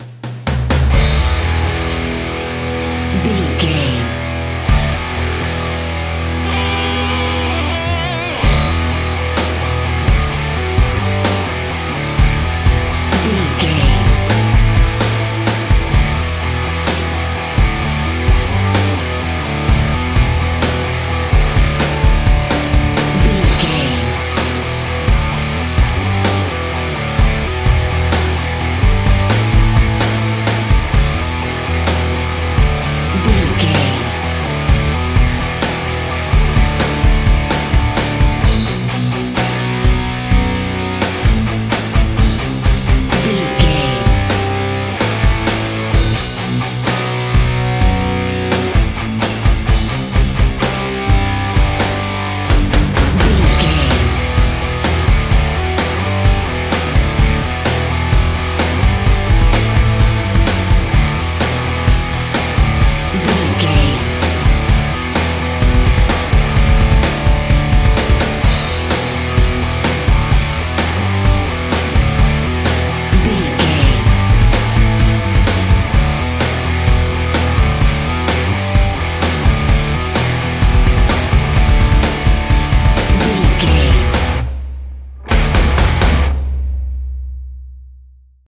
Ionian/Major
electric guitar
bass guitar
drums
pop rock
hard rock
metal
lead guitar
aggressive
energetic
intense
powerful
nu metal
alternative metal